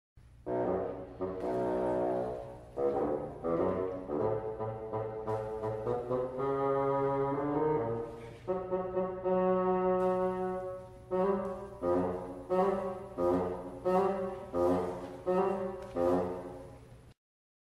• Simfoniskā pasaka "Pēterītis un vilks" (SR) Saklausa mūzikas instrumentiem atbilstošus tēlus